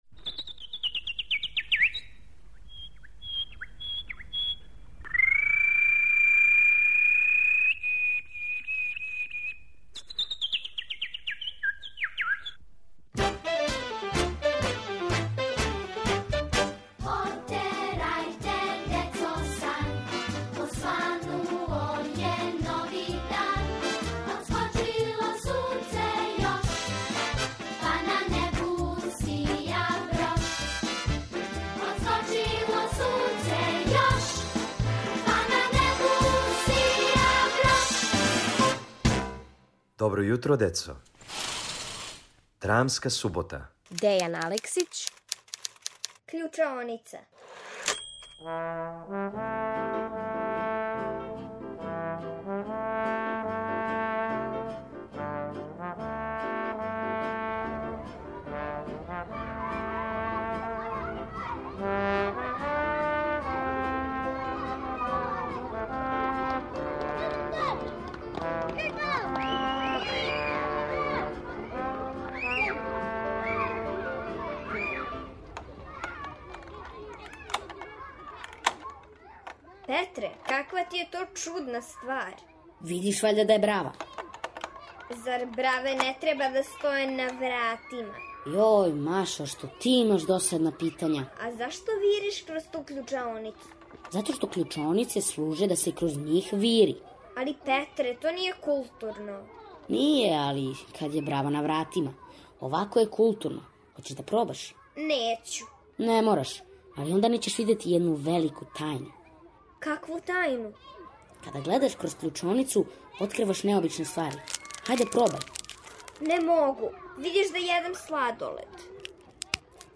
Шта се то види кроз кључаоницу браве која није на вратима, сазнајте у краткој драми Дејана Алексића.